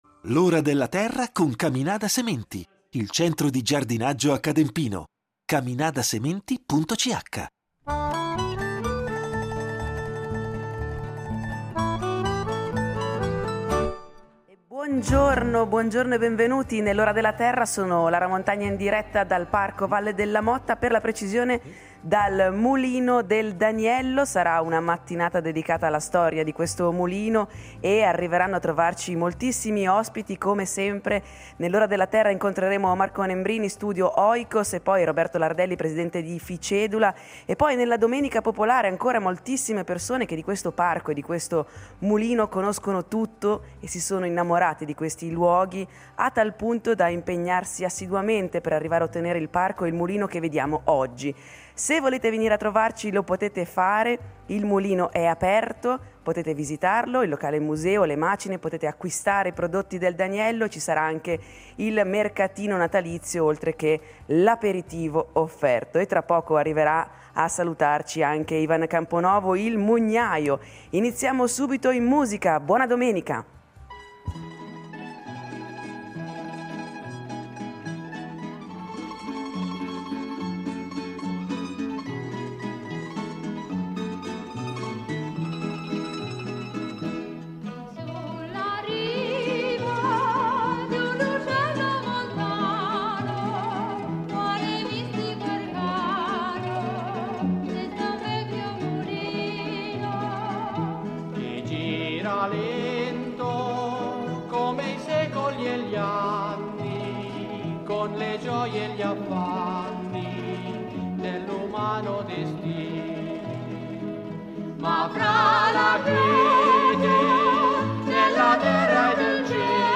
In diretta su Rete Uno